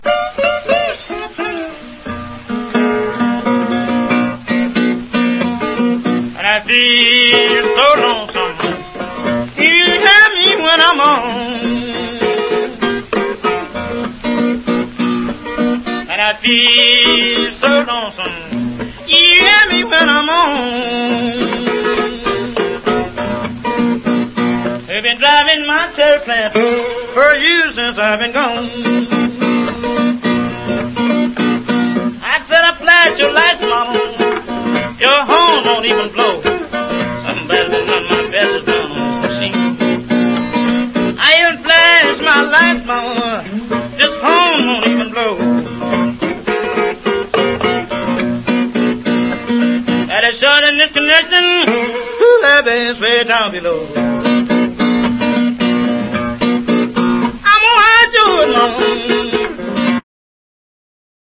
EASY LISTENING / VOCAL / 70'S ROCK / S.S.W.
オリジナルに忠実なカヴァーになっています。